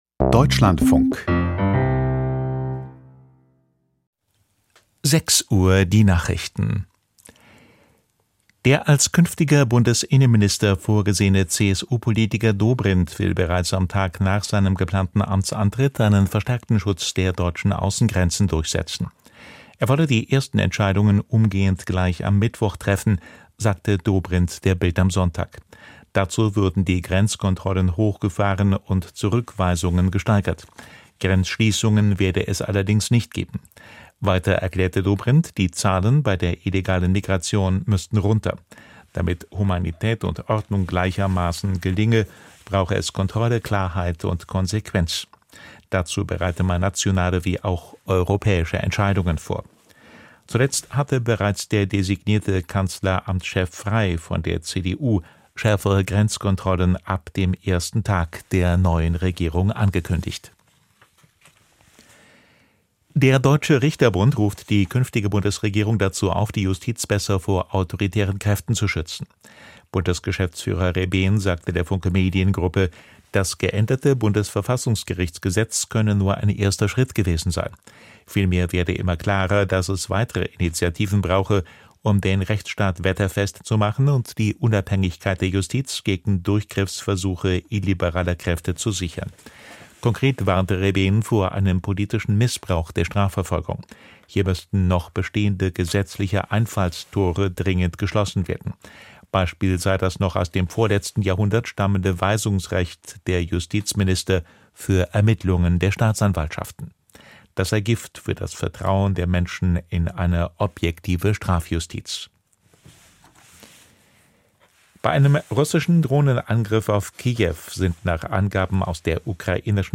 Die Deutschlandfunk-Nachrichten vom 04.05.2025, 06:00 Uhr